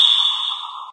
whoosh_down_3.ogg